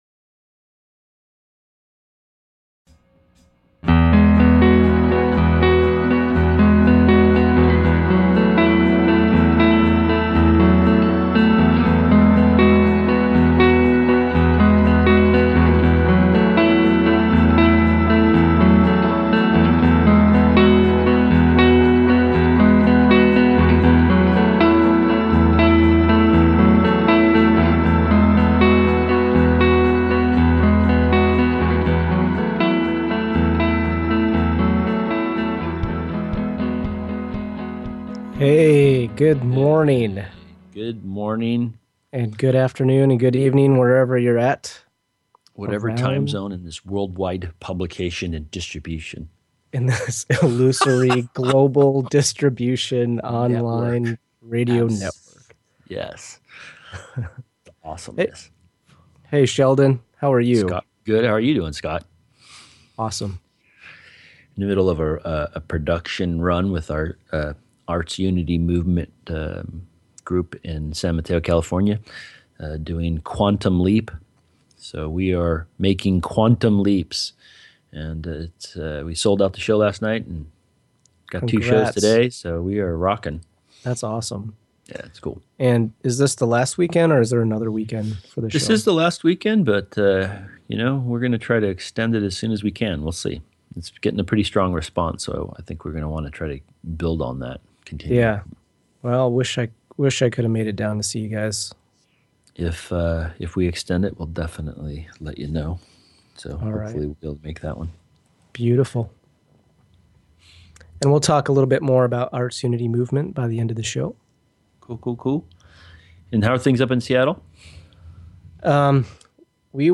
The podcast also includes a meditation at the beginning which takes you on a beautiful journey to connect with Christ/Christ Consciousness.